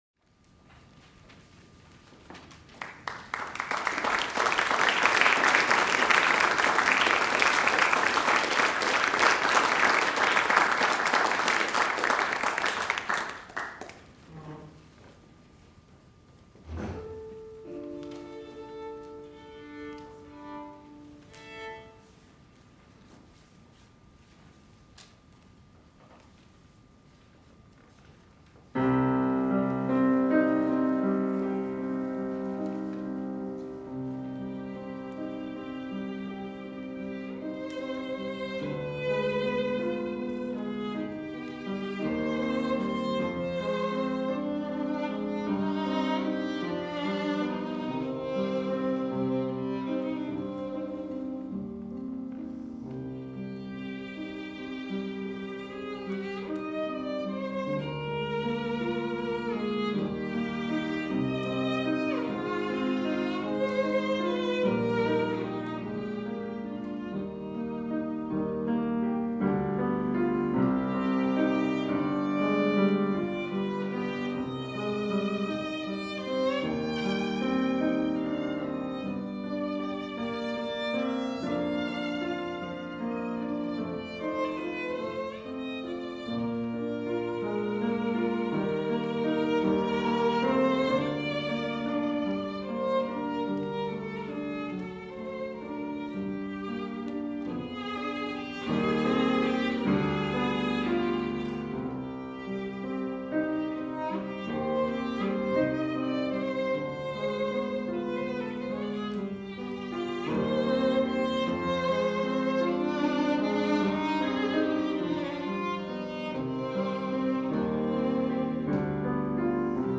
As part of its series Mystic Chords of Memory: Abraham Lincoln and the Performing Arts, on February 5, 2009, the Library for the Performing Arts featured a re-creation of a performance attended by Abraham Lincoln on March 24, 1864.
piano
soprano
tenor
violin